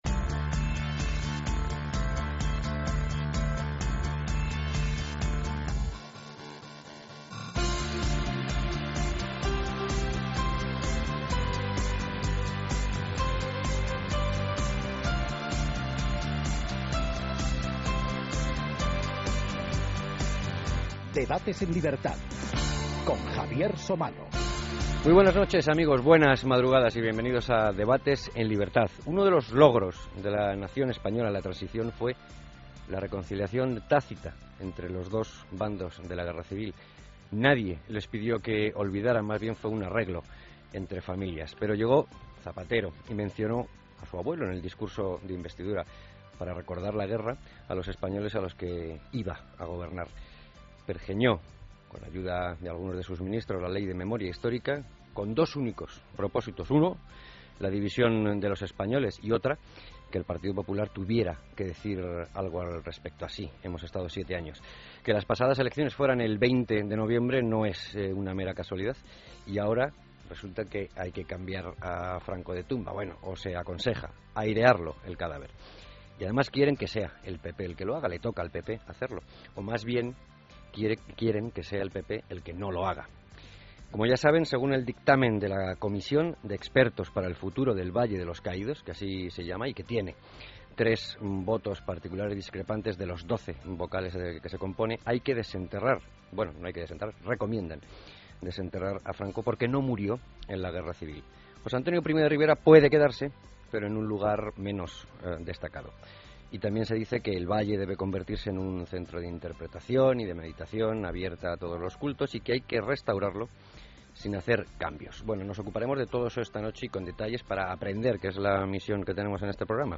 Tertulia en Esradio sobre el Valle de los Caídos - ADVC